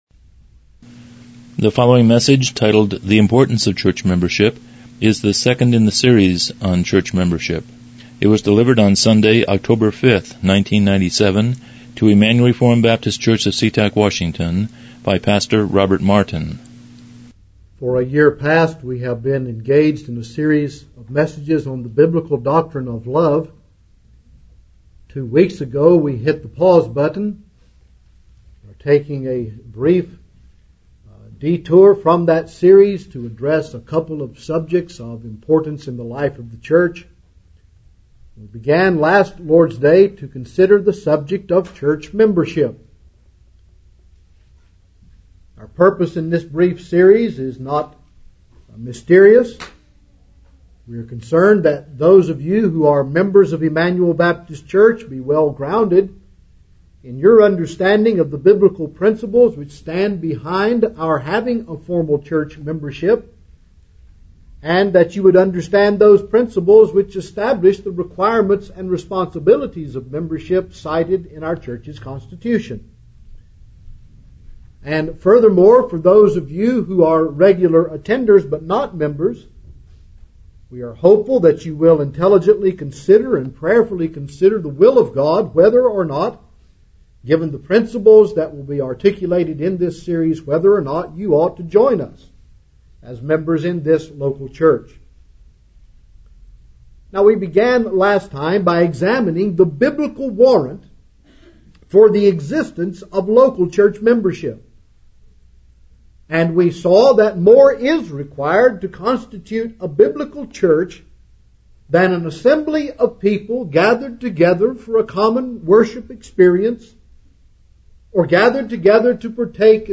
Church Membership Service Type: Morning Worship « 01 The Warrant for Church Membership 03 Requirements for